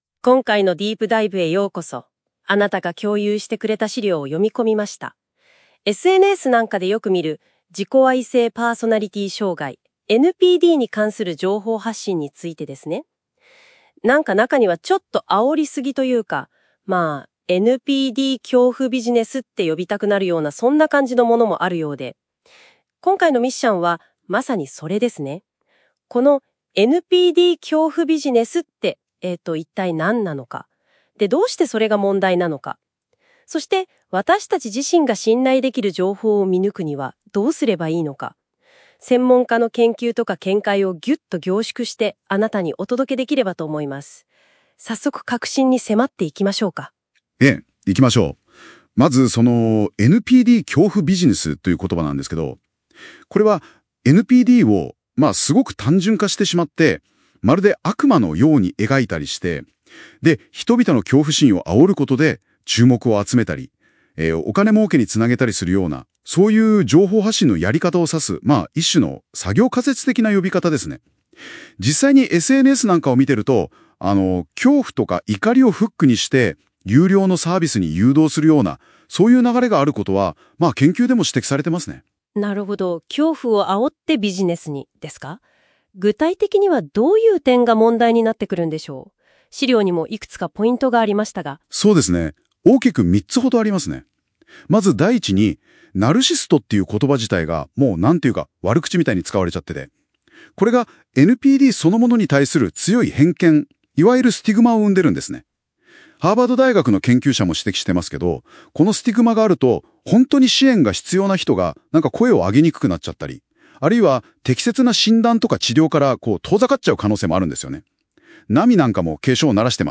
AI音声解説もご用意しました